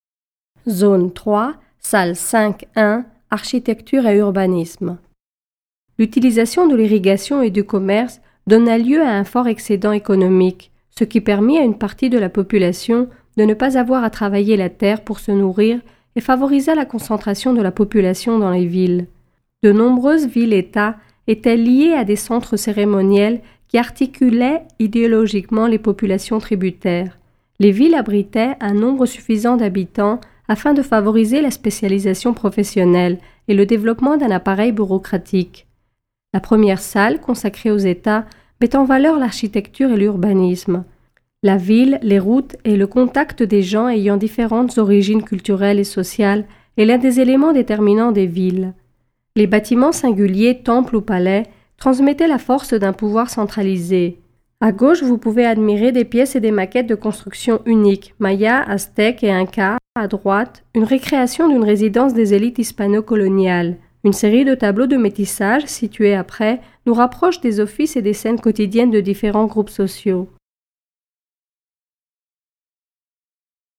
Audioguides par pièces